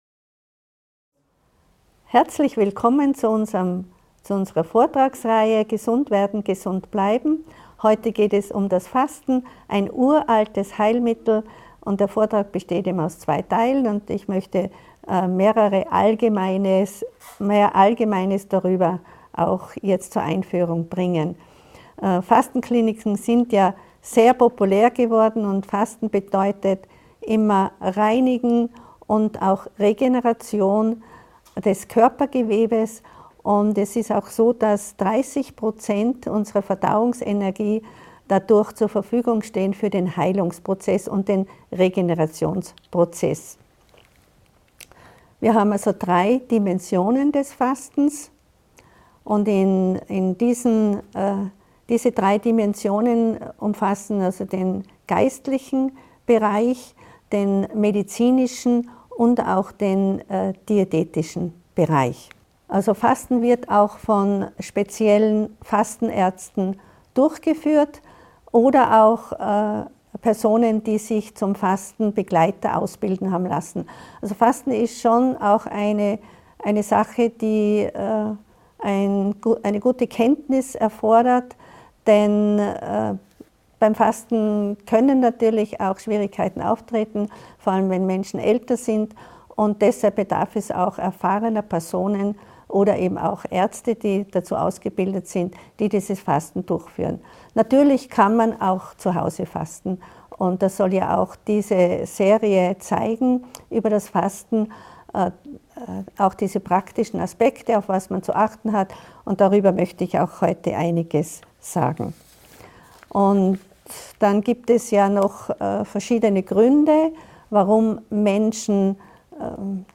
In dieser inspirierenden Vortragsreihe rund ums Fasten wird gefragt, wie die alte Praxis nicht nur die körperliche Gesundheit fördert, sondern auch die Seele reinigt. Von wissenschaftlichen Grundlagen über biblische Aspekte bis hin zu praktischen Tipps wird gezeigt, wie Fasten in den Alltag integriert werden kann.